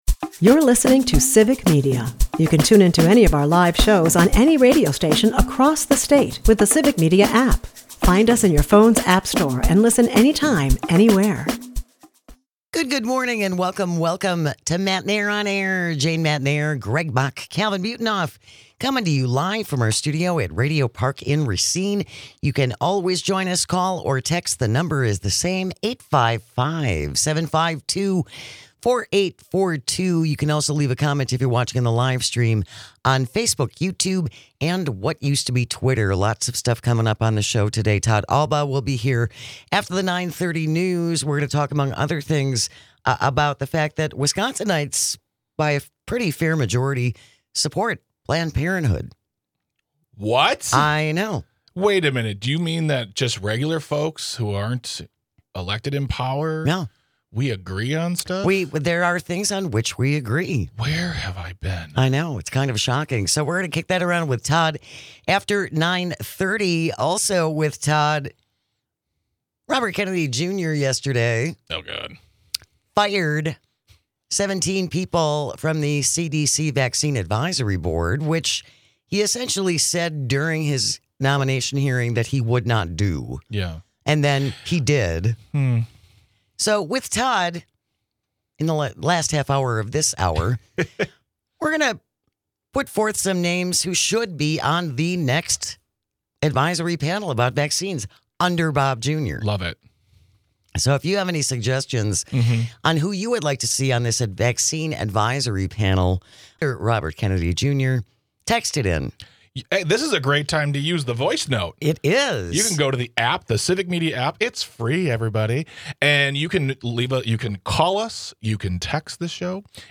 We're building a state-wide radio network that broadcasts local news